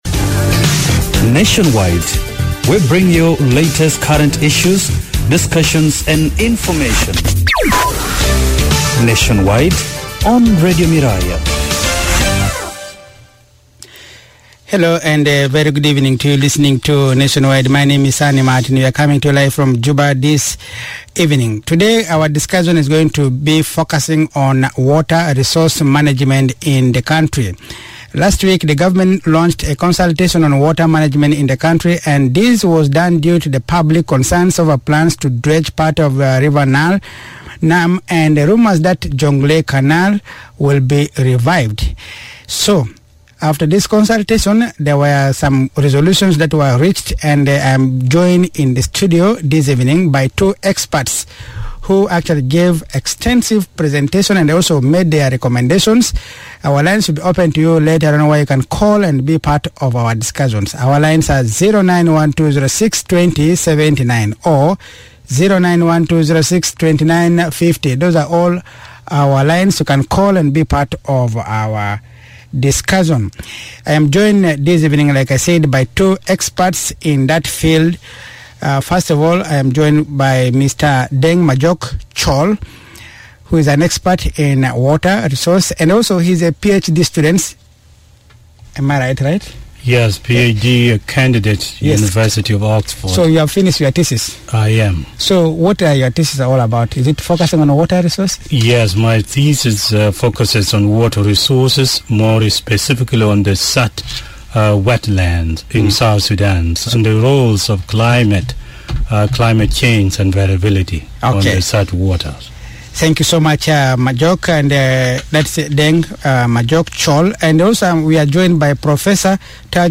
Nationwide Discussion on Dredging